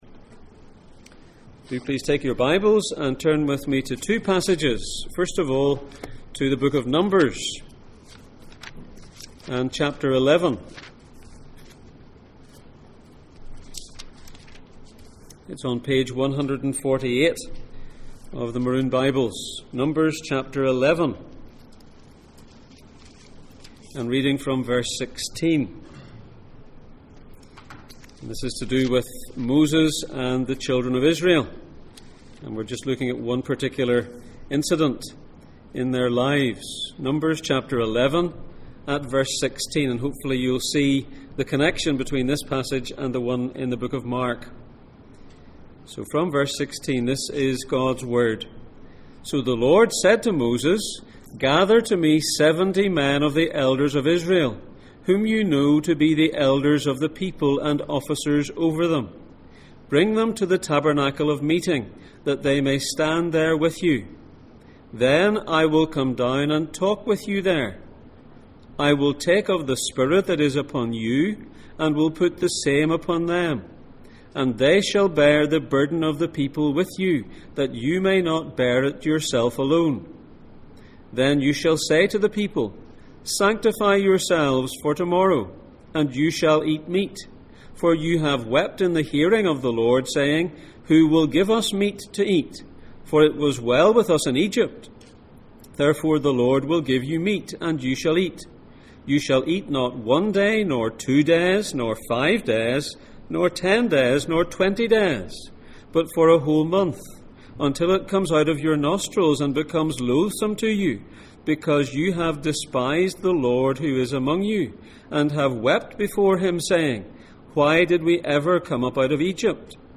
Jesus in Mark Passage: Mark 9:38-42, Numbers 11:16-30 Service Type: Sunday Morning %todo_render% « Who is the greatest?